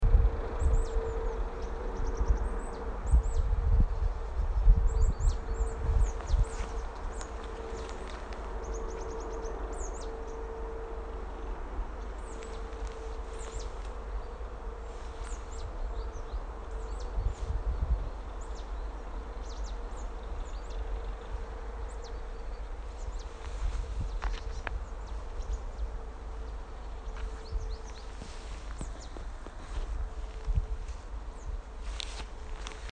mobile recordings
ParpalKoitsanlahti1.mp3